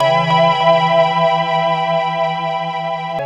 Synth Chord 03.wav